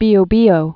(bēō-bēō)